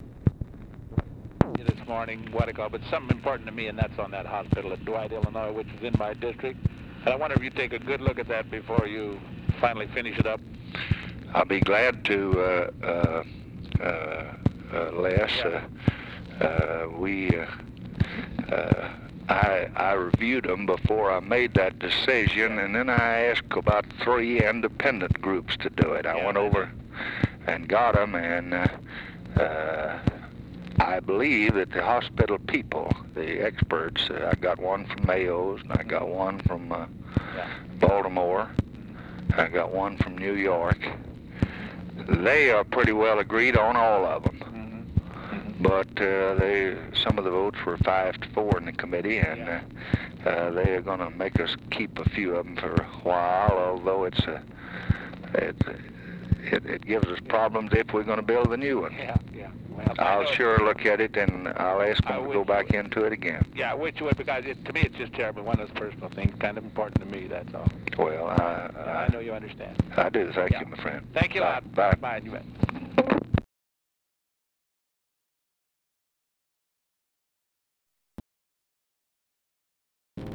Conversation with LESLIE ARENDS, May 12, 1965
Secret White House Tapes